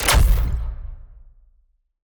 LaserFire.wav